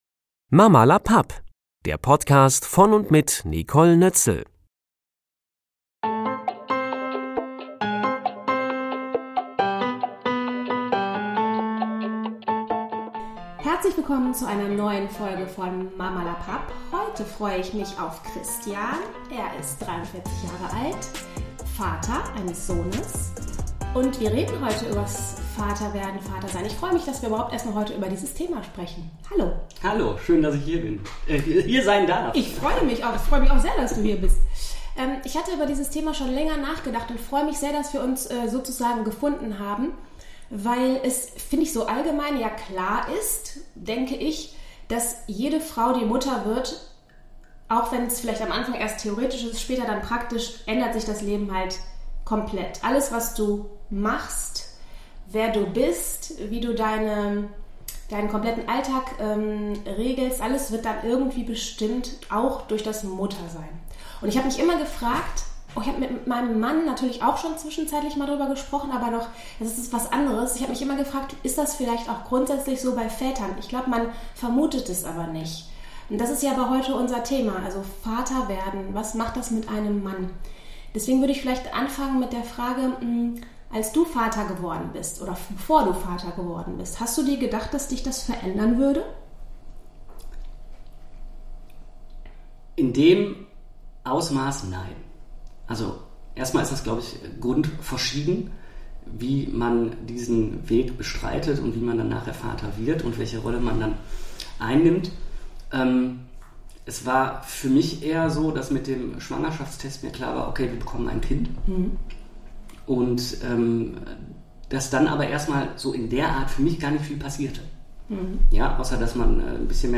Das Vater-Werden hat ihn verändert. Warum er das dennoch nicht vermisst aber warum er sich nicht verzeihen kann, keine Elternzeit genommen zu haben, das verrät er mir im Interview.